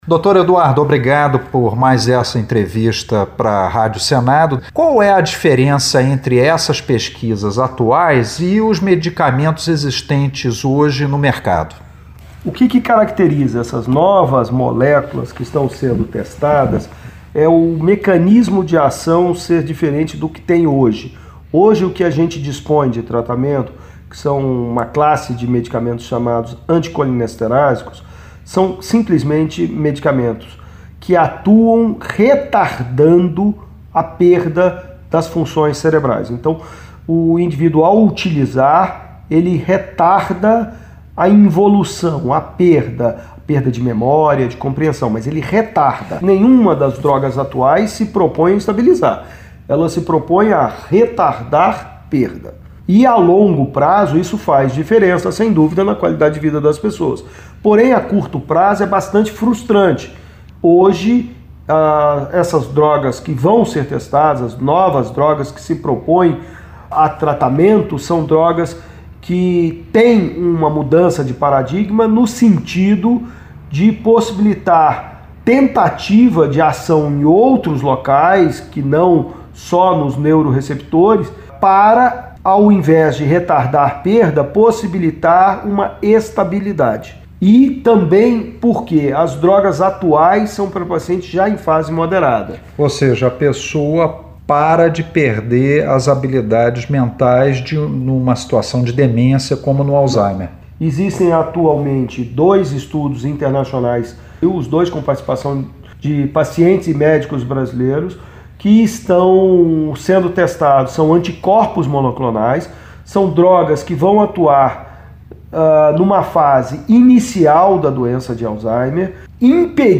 Geriatra fala sobre pesquisas com novos medicamentos para Alzeihaimer